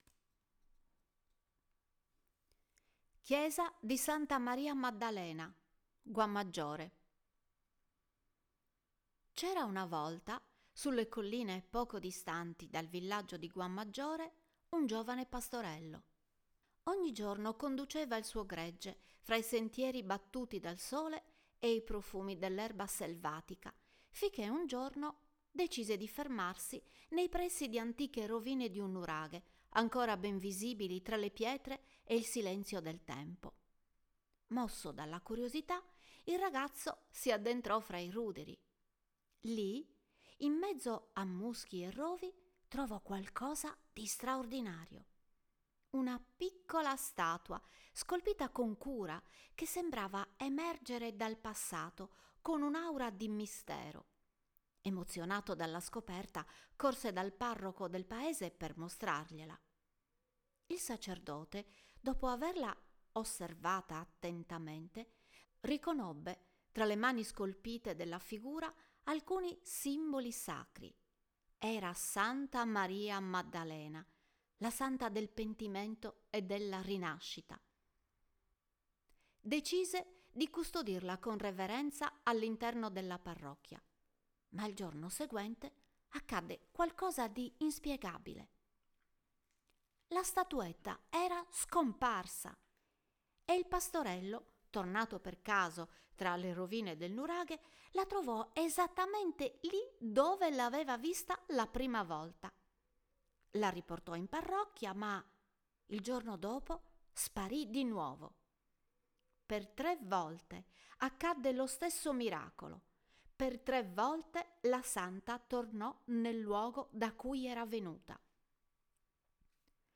Voce Narrante